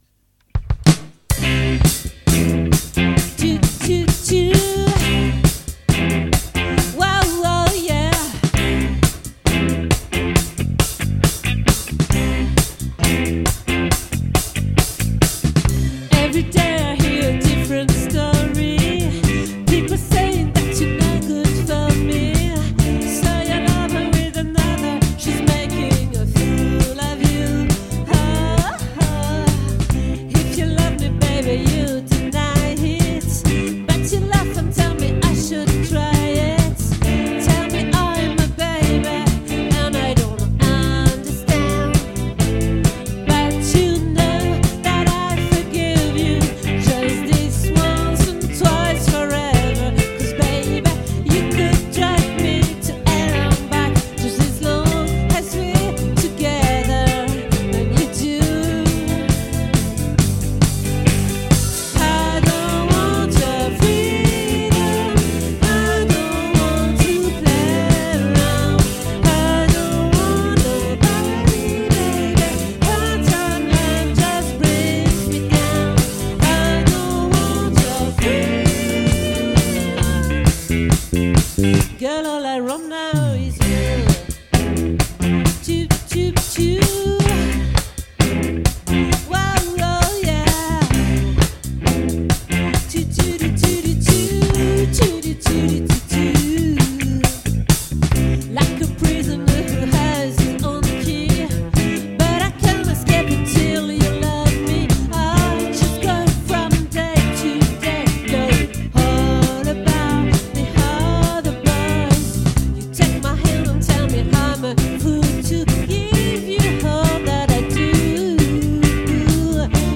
🏠 Accueil Repetitions Records_2024_02_20_OLVRE